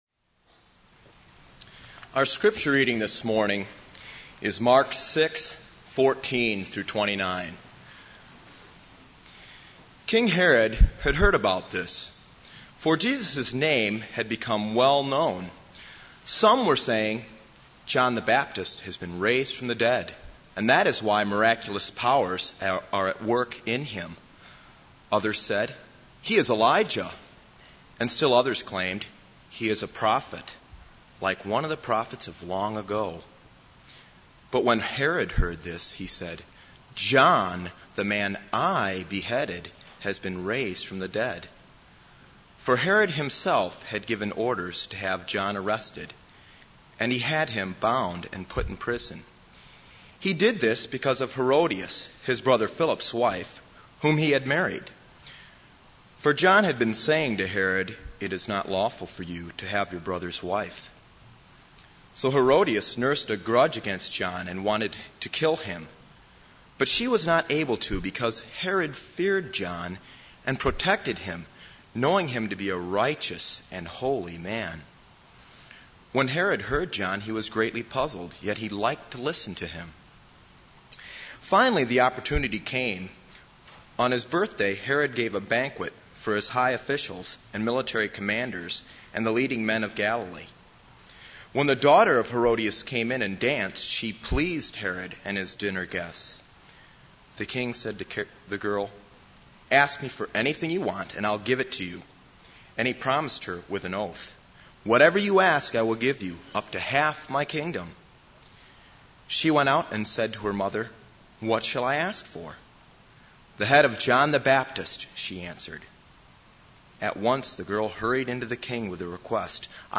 This is a sermon on Mark 6:14-29.